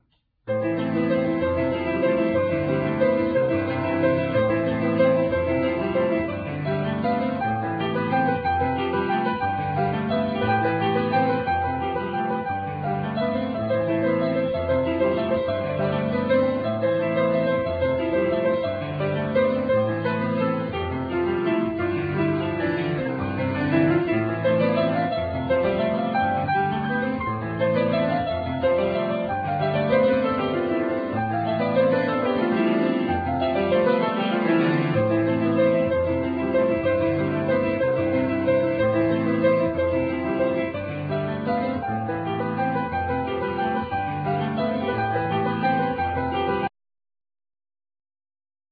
Piano(YAMAHA)